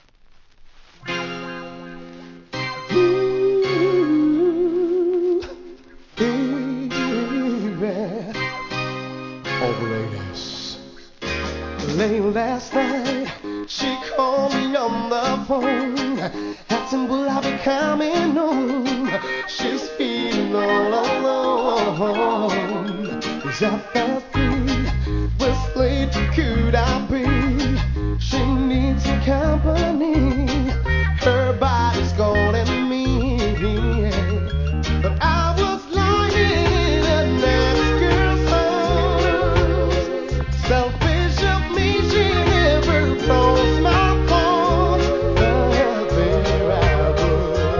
REGGAE
美メロNICEヴォーカル!!